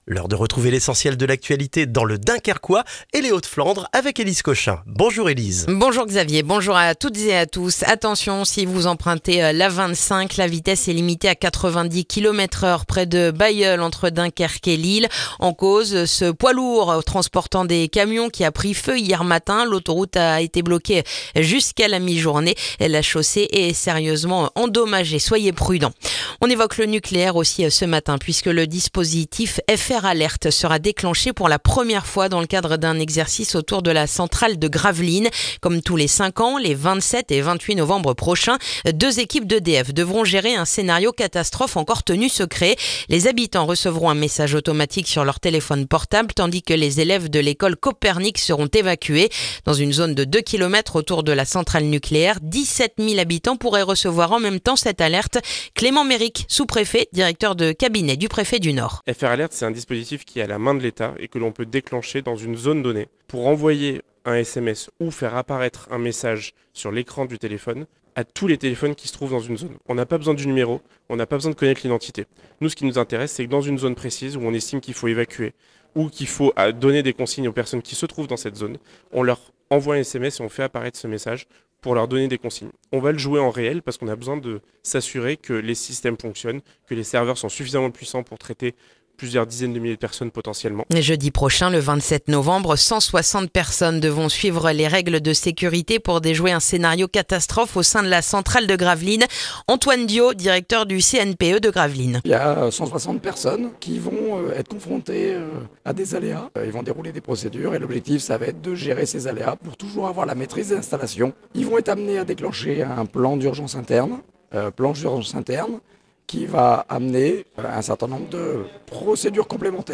Le journal du mercredi 19 novembre dans le dunkerquois